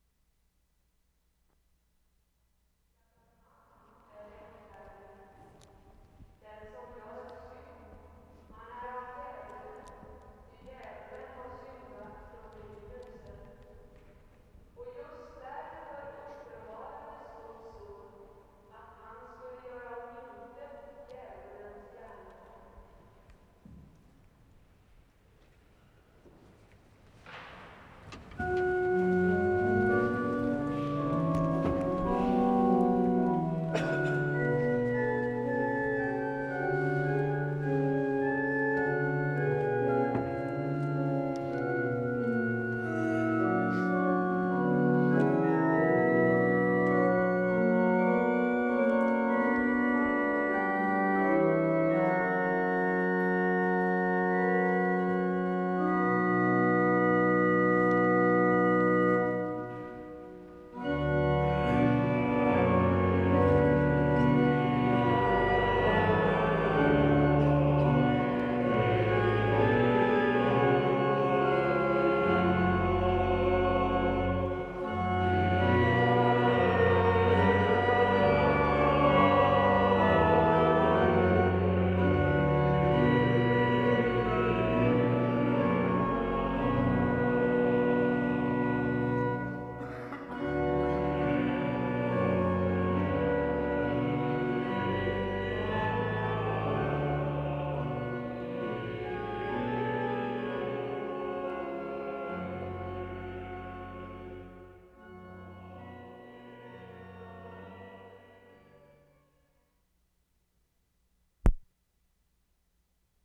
WORLD SOUNDSCAPE PROJECT TAPE LIBRARY
Stockholm, Sweden Feb. 14/75
INSIDE STORKYRKAN, CONTINUATION OF SERVICE
Female priest and congregation sing.